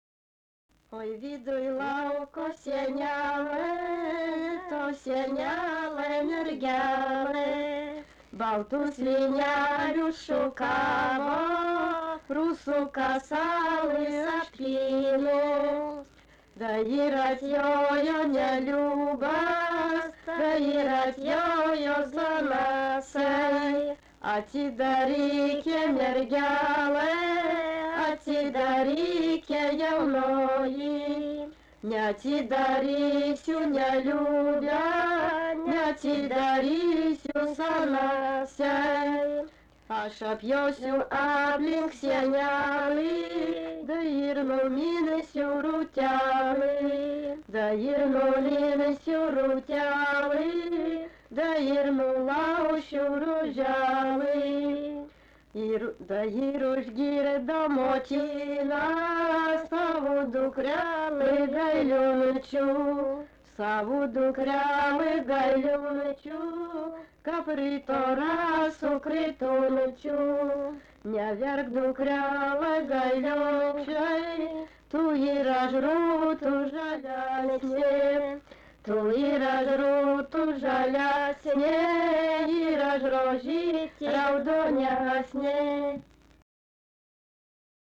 vokalinis
LMTA Mokslo centro muzikinio folkloro archyvas